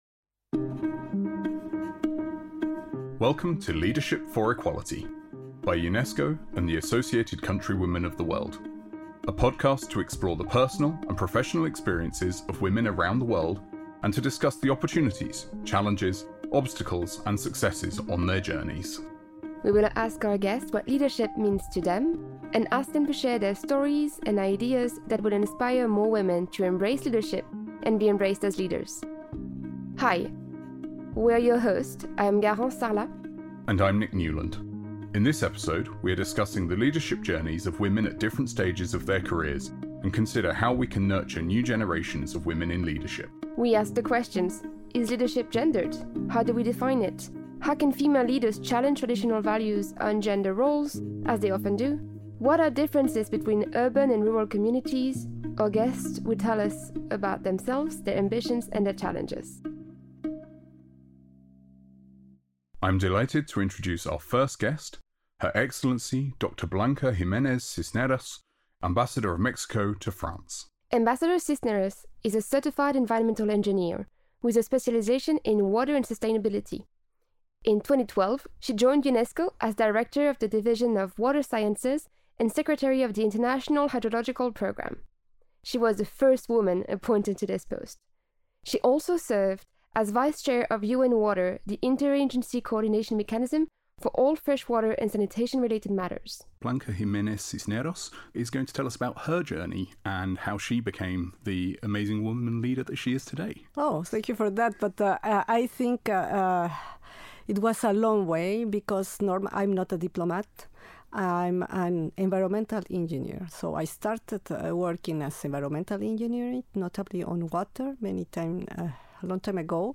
UNESCO’s "Leadership for Equality" podcast features inspiring discussions on gender equality.